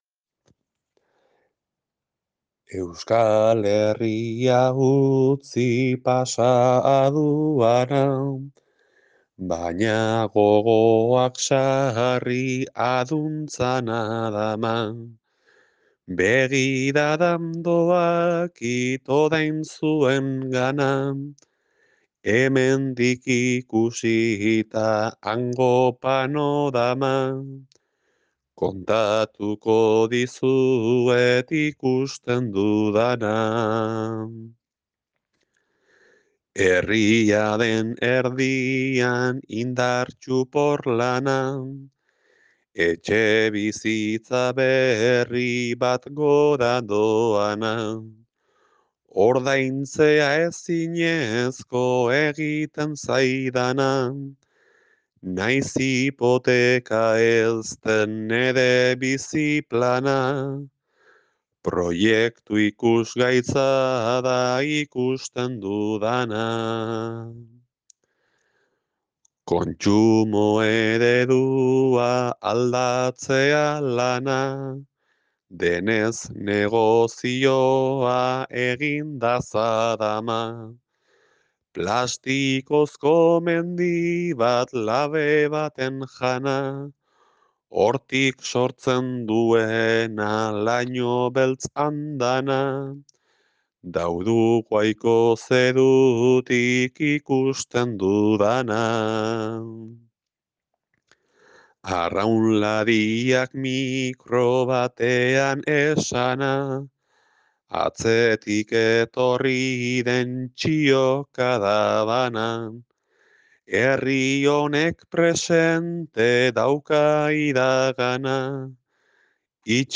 Doinua: Hamalau heriotzena